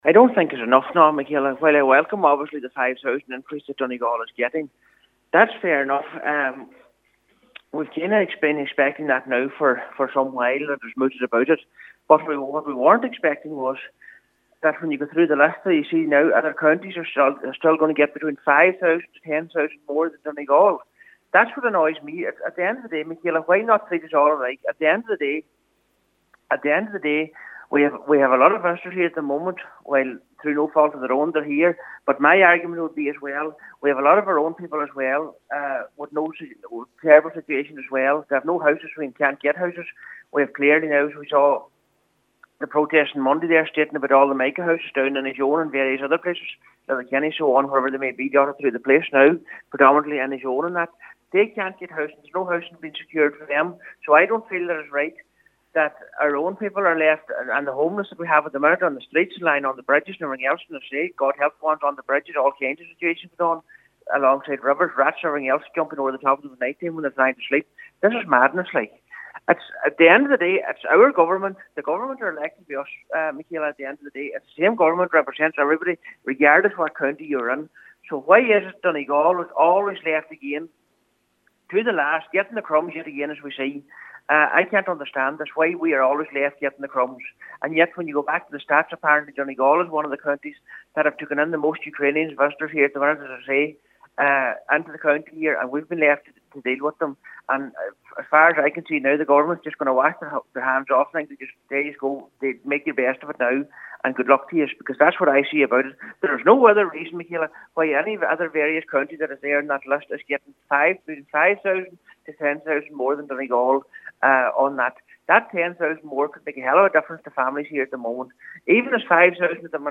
Councillor Michael McClafferty says much more needs to be done for people in Donegal: